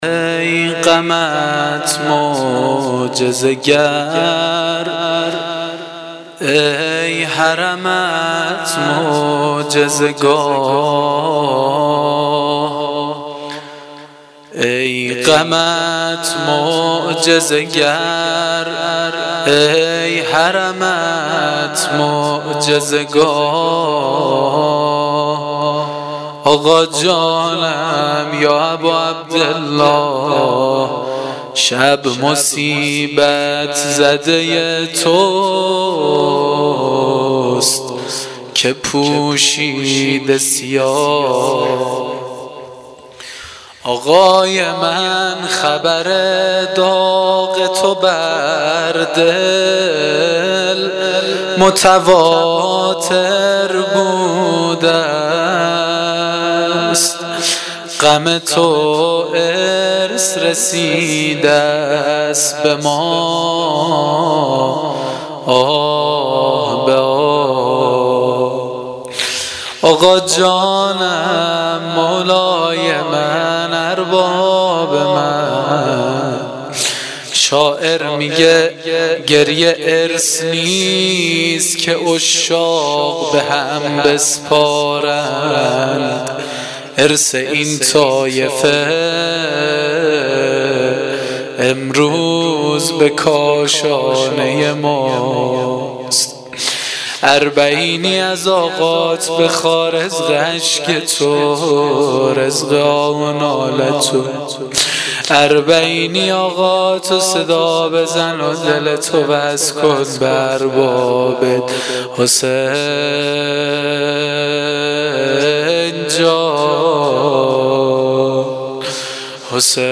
روضه شام اربعین.mp3